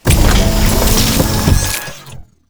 droidic sounds